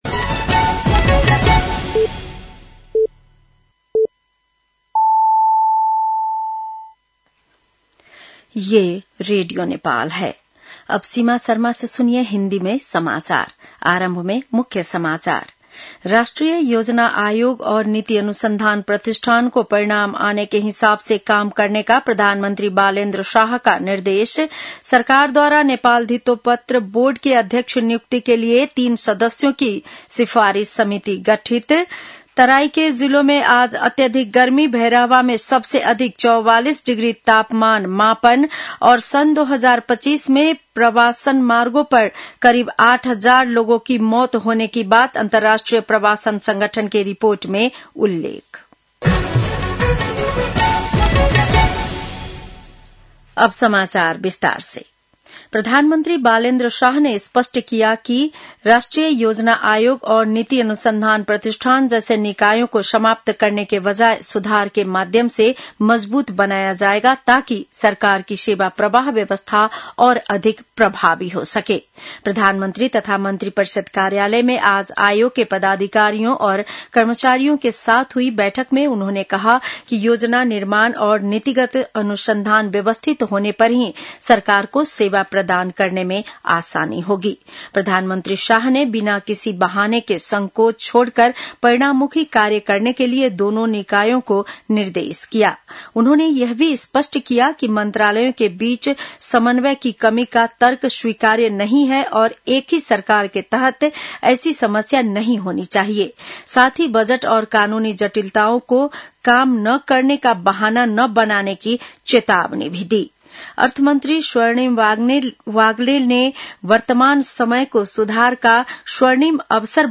बेलुकी १० बजेको हिन्दी समाचार : ८ वैशाख , २०८३
10-pm-hindi-news-1-08.mp3